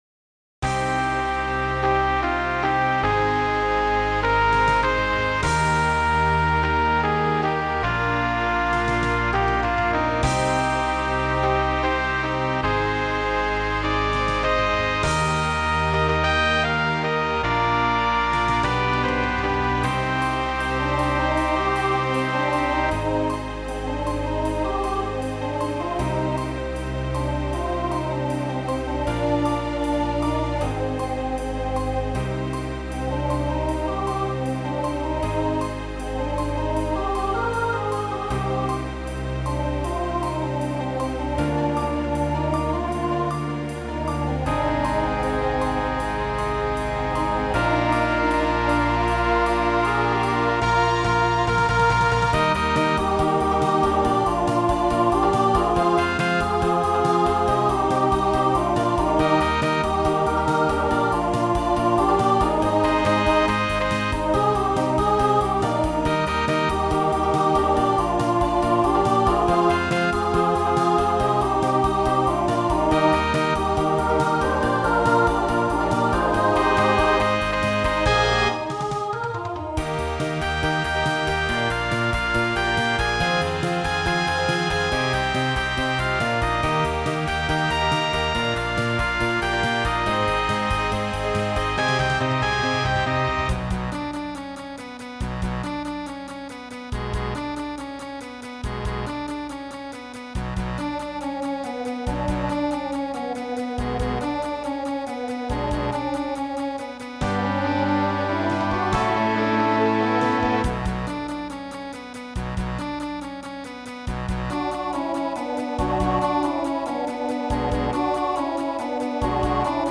Voicing Mixed Instrumental combo Genre Pop/Dance , Rock